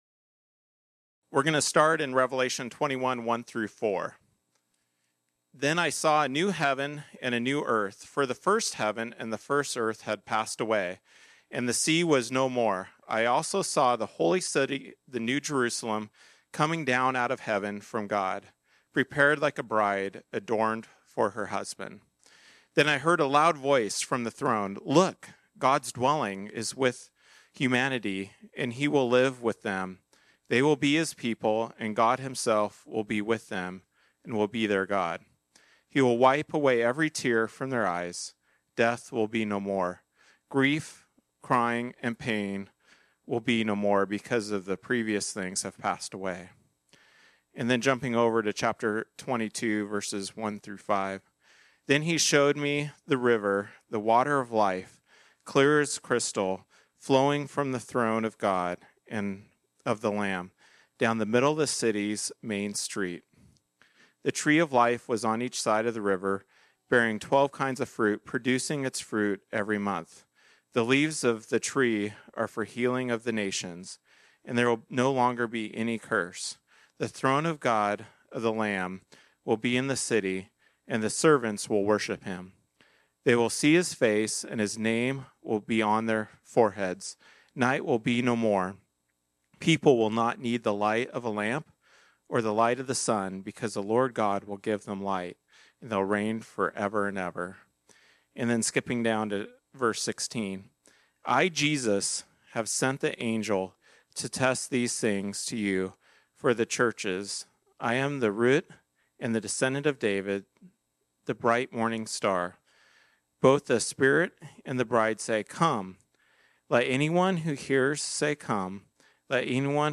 This sermon was originally preached on Sunday, December 29, 2024.